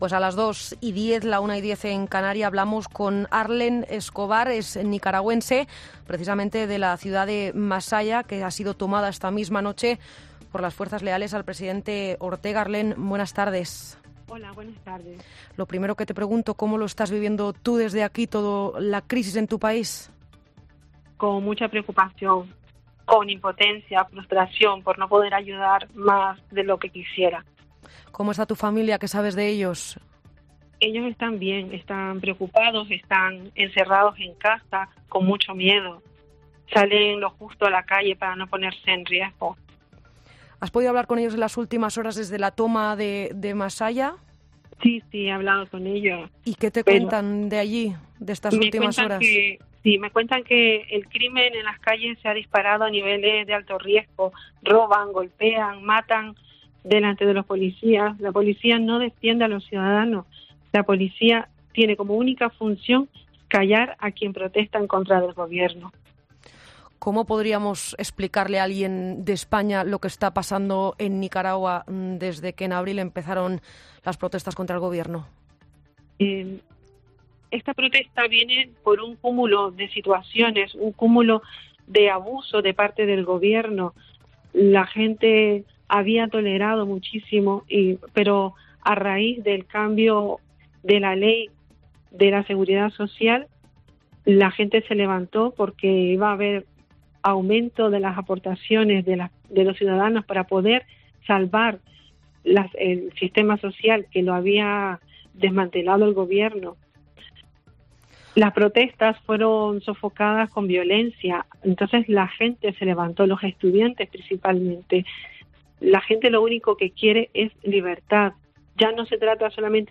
"Siento mucha impotencia y frustración por no poder ayudar más de lo que quisiera. Anoche pude hablar con mi familia y todos están bien. En estos momentos, están encerrados en casa, preocupados y con mucho miedo", ha relatado la joven este miércoles al programa 'Mediodía COPE'.